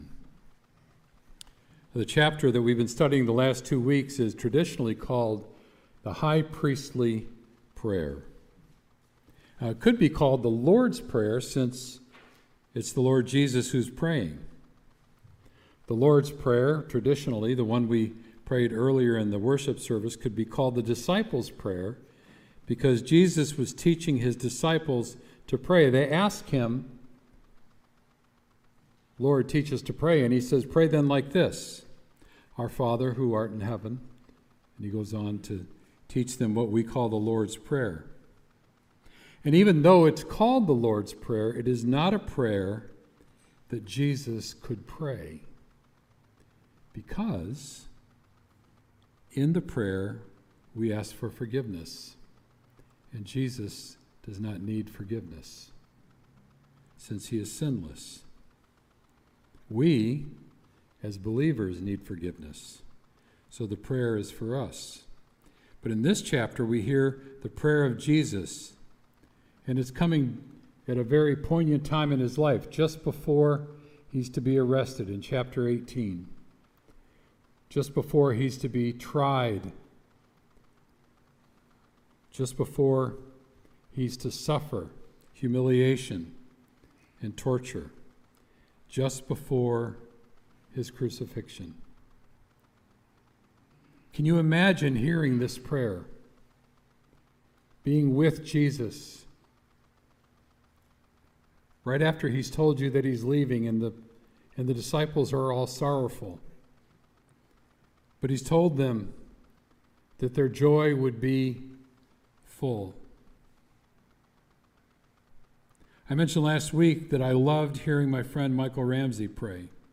Sermon “The High Priestly Prayer